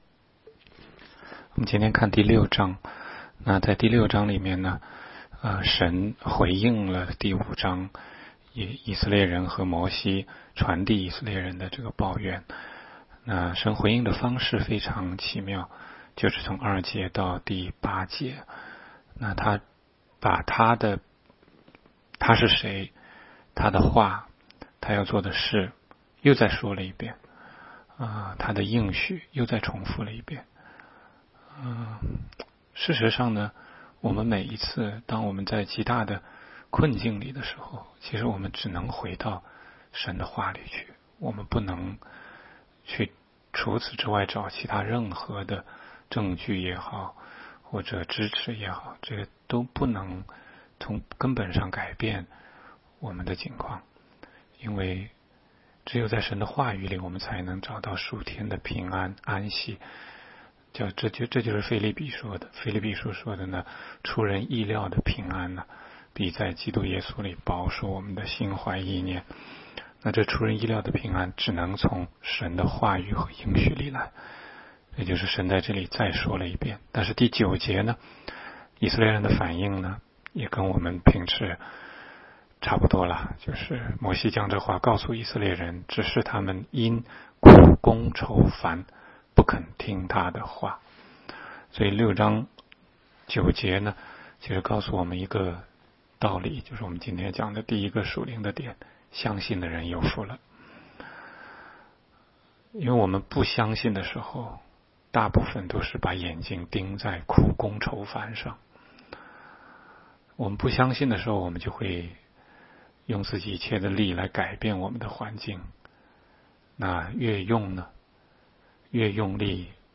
16街讲道录音 - 每日读经-《出埃及记》6章